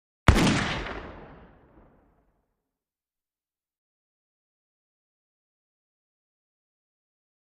Musket Fire
Black Powder Musket 4, Single Shot. Powerful With Less Ring Off Than FX 20.